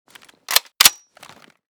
mp5_unjam.ogg